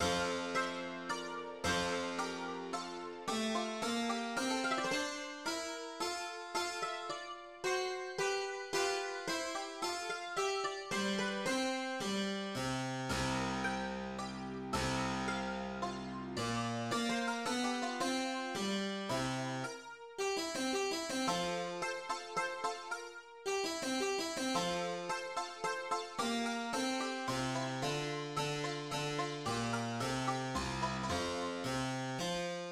Genre Menuet
Effectif Clavecin
Elle est habituellement interprétée au clavecin, mais elle peut-être jouée sur d'autres instruments à clavier.
Comme l'indique le tempo, c'est une pièce assez rapide, écrite en forme binaire. Elle est formée de deux parties, avec des signes de répétition à la fin de chaque section.
Comme menuet, elle est par définition élégante, majestueuse dans le style.